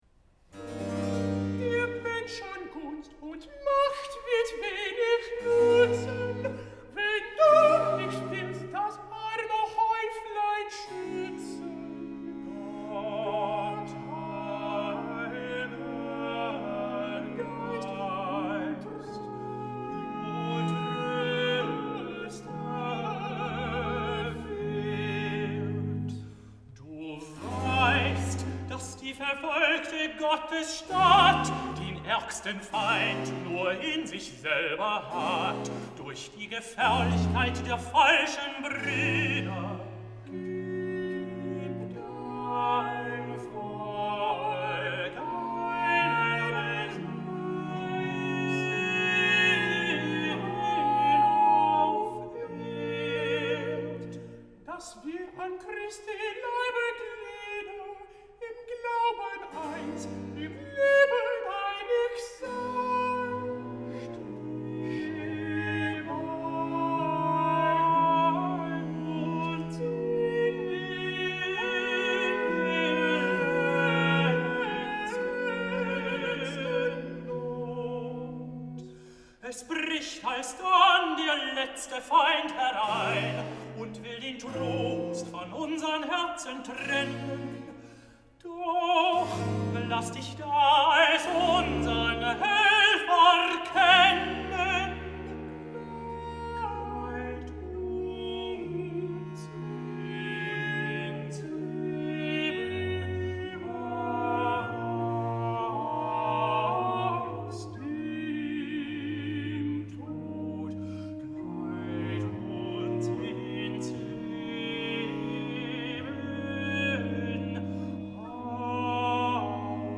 (Aufführung: am 27.02.2000 in Southwell Minster, UK)